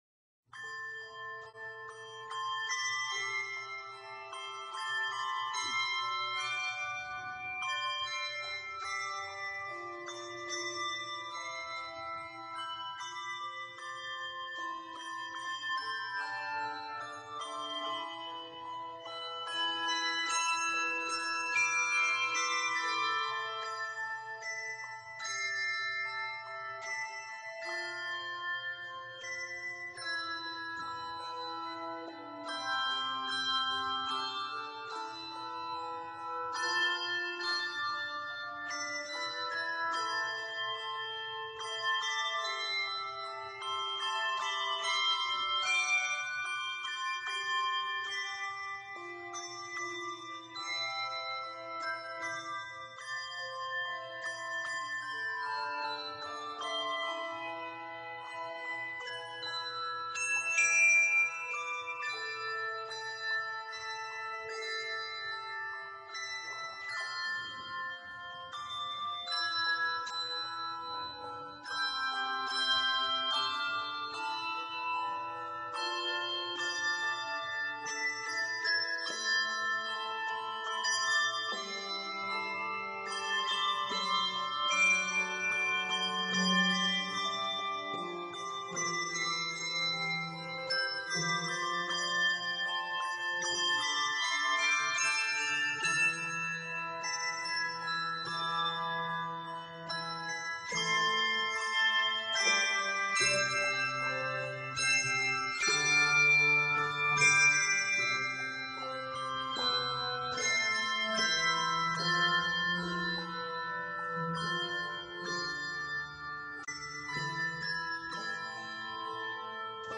The arranger of this hymn tune